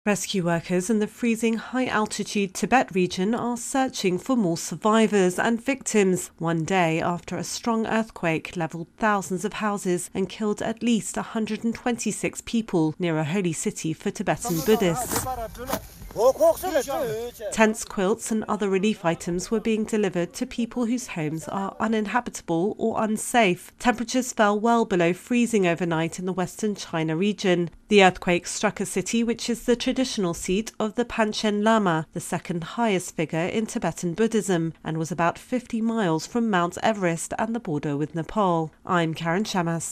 reports on rescue efforts following a strong earthquake in Tibet.